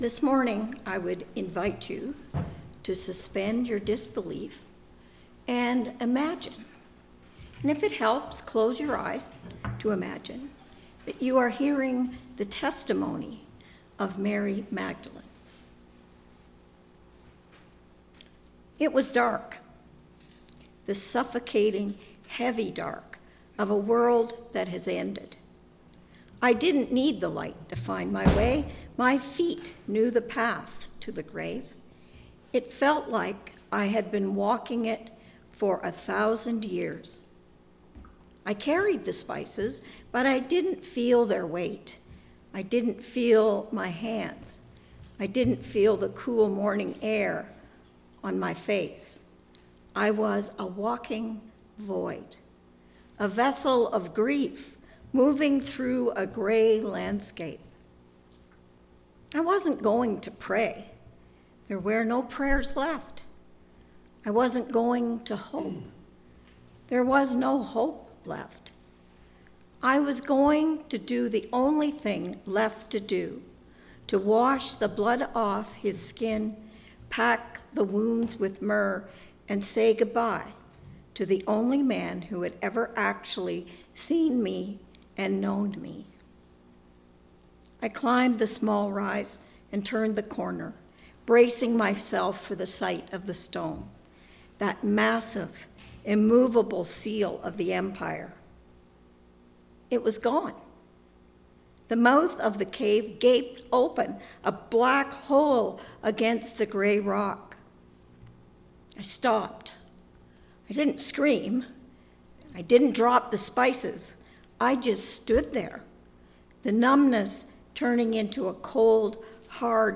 Easter Reflection